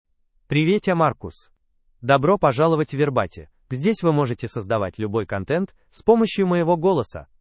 MaleRussian (Russia)
Marcus — Male Russian AI voice
Marcus is a male AI voice for Russian (Russia).
Voice sample
Male